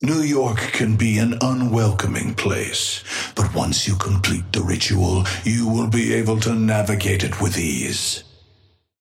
Amber Hand voice line - New York can be an unwelcoming place, but once you complete the ritual, you will be able to navigate it with ease.
Patron_male_ally_viscous_start_04.mp3